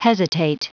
Prononciation du mot hesitate en anglais (fichier audio)
Prononciation du mot : hesitate